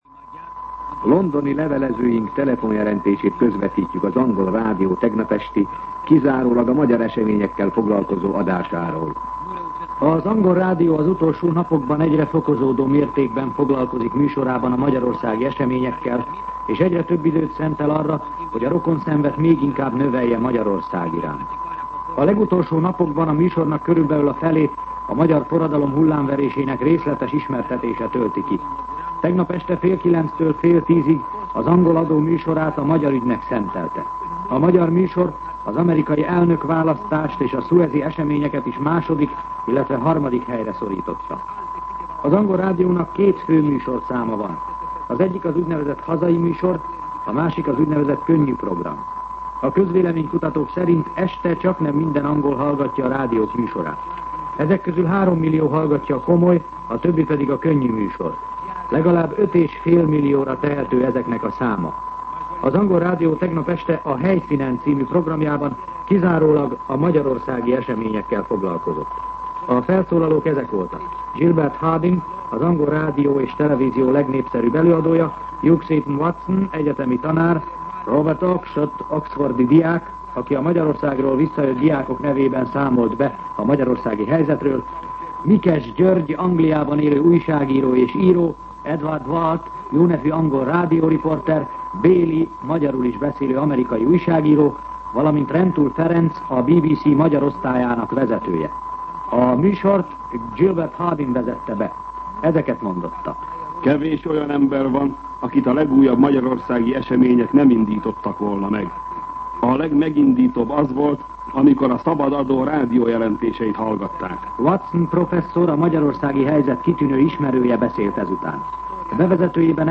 Londoni levelezőink telefonjelentése